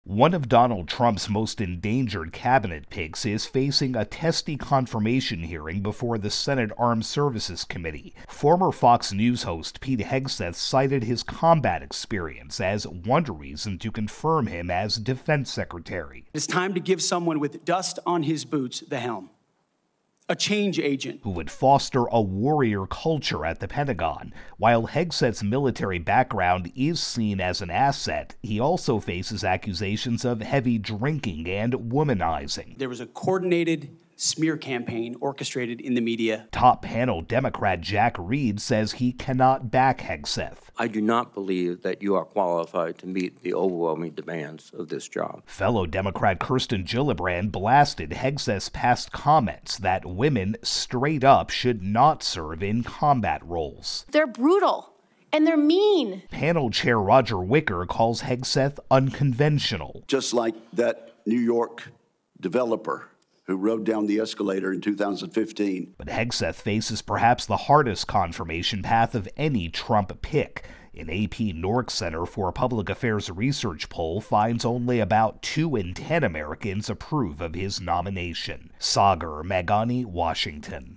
reports on Defense Secretary nominee Pete Hegseth's confirmation hearing before the Senate Armed Services Committee.